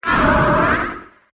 runerigus_ambient.ogg